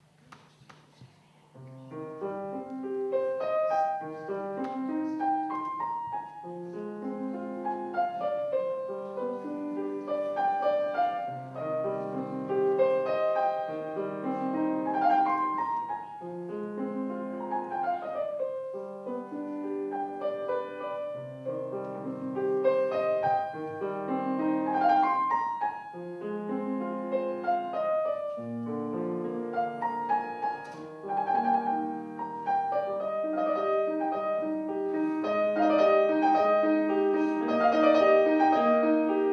Andrea Bacchetti, pianista - Domenica 19 ottobre 2008 - B. Galuppi Larghetto - Sonata in Do Min